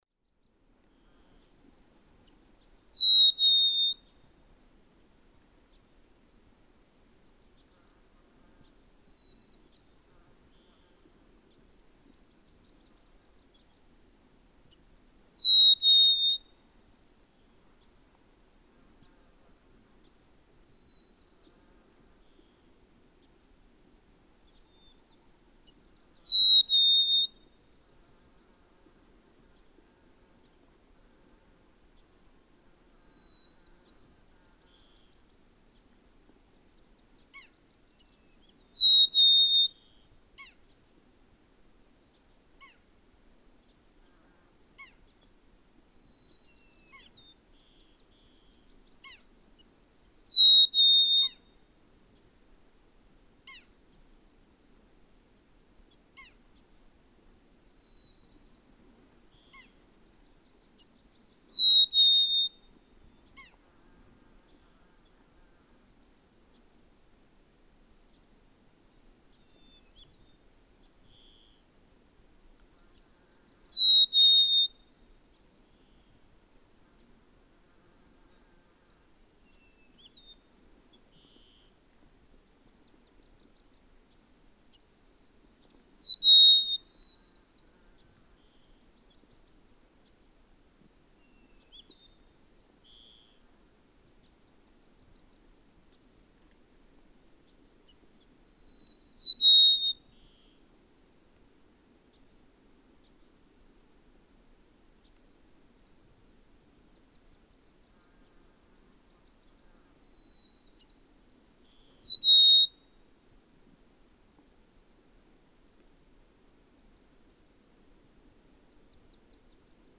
Golden-crowned sparrow
Ohhhhh, deeaaar mee, he seems to sing, but he seems to have two different songs. The first occurs seven times until 1:15, but then a second, longer variant occurs at 1:25. You can hear a local white-crowned sparrow in the background (best heard beginning about 1:10).
Mile post 34, Denali Highway, Alaska.
Doesn't it sound as if he has two different songs?
697_Golden-crowned_Sparrow.mp3